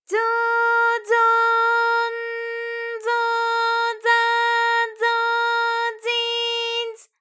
ALYS-DB-001-JPN - First Japanese UTAU vocal library of ALYS.
zo_zo_n_zo_za_zo_zi_z.wav